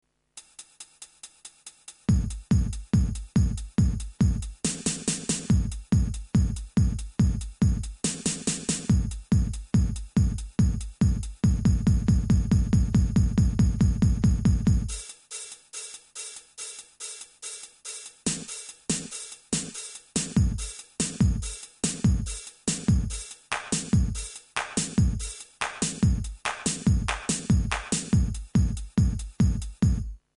All done live demonstrating how easy (and fun) it is to get some great riffs.
Small loop using drums.